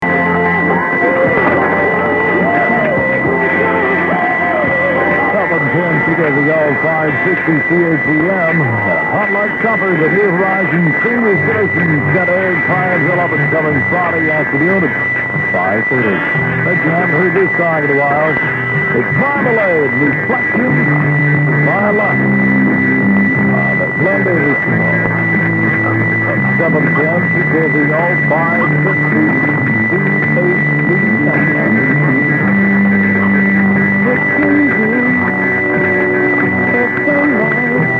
I have even wrestled out some old manky tapes with old vintage ID's to play to you.